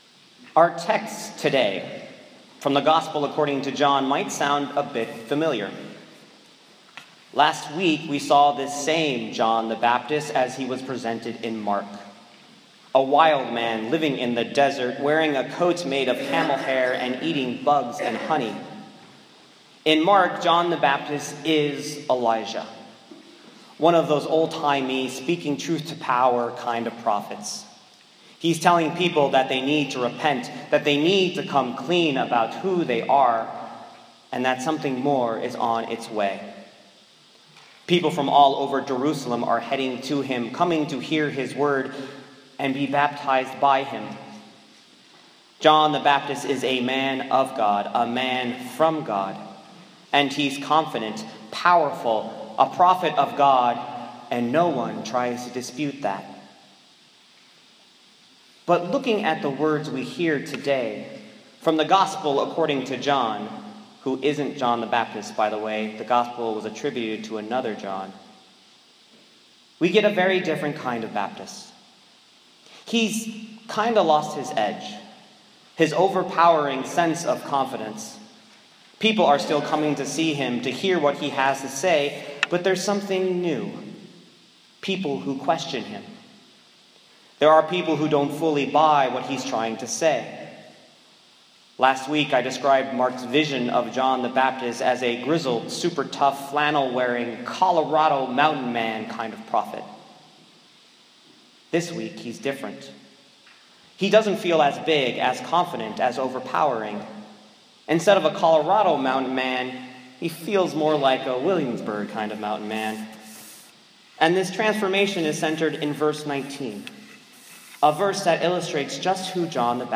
John 1:6-8,19-28 My sermon from 3rd Sunday of Advent (December 14, 2014) on John 1:6-8,19-28.